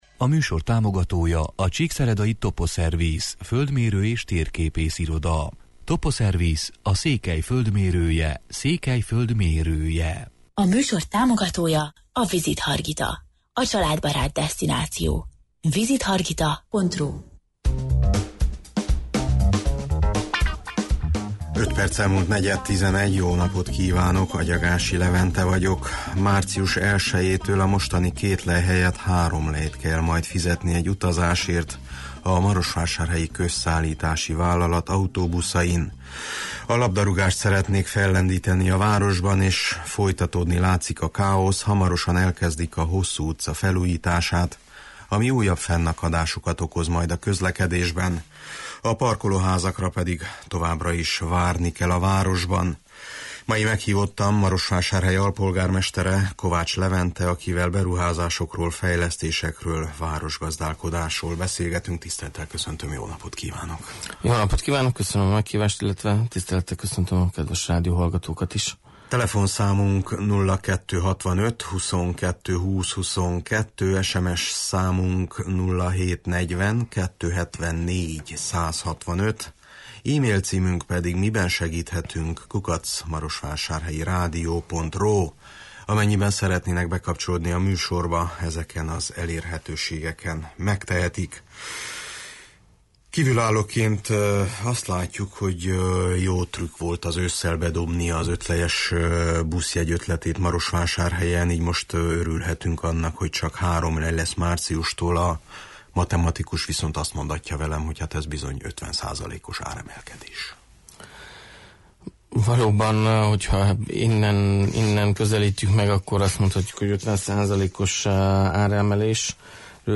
Mai meghívottam Marosvásárhely alpolgármestere, Kovács Levente, akivel beruházásokról, fejlesztésekről, városgazdálkodásról beszélgetünk: